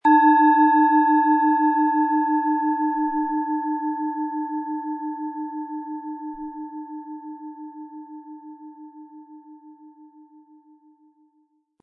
Tibetische Becken- und Bauch-Klangschale, Ø 12,5 cm, 180-260 Gramm, mit Klöppel
Aber dann würde der ungewöhnliche Ton und das einzigartige, bewegende Schwingen der traditionellen Herstellung fehlen.
HerstellungIn Handarbeit getrieben
MaterialBronze